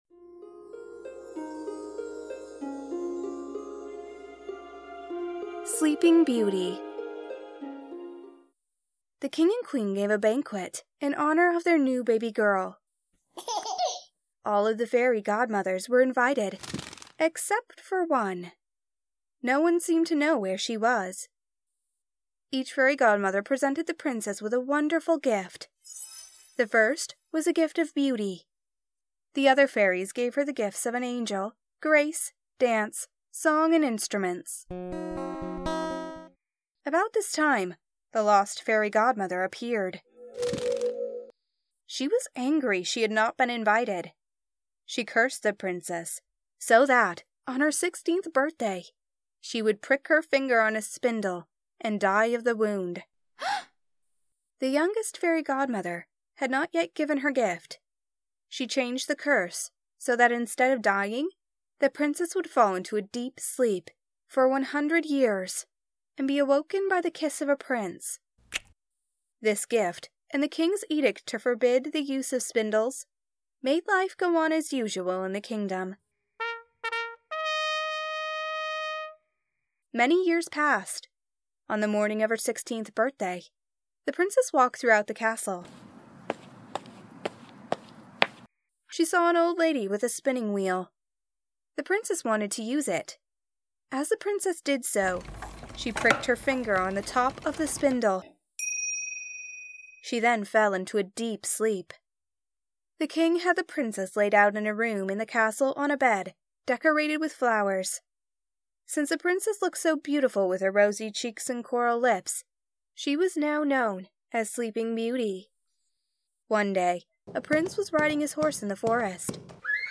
Free ‘Sleeping Beauty’ Audiobook Story for your mother